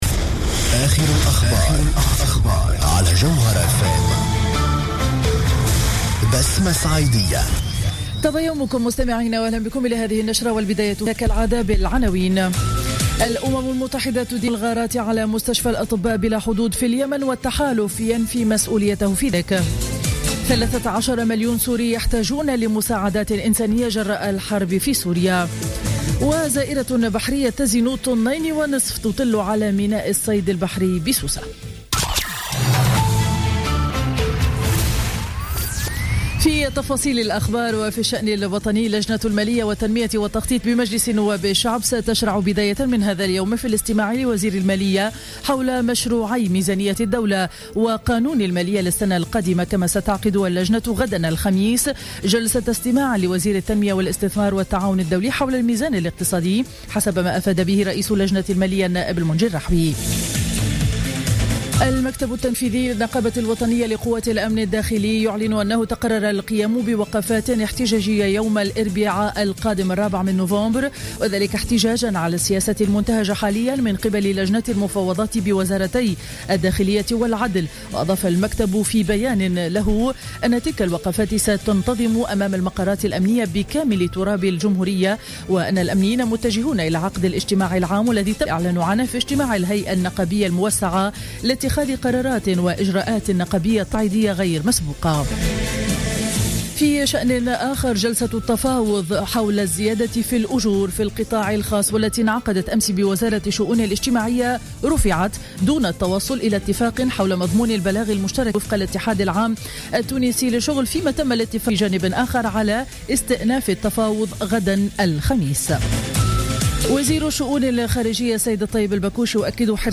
نشرة أخبار السابعة صباحا ليوم الأربعاء 28 أكتوبر 2015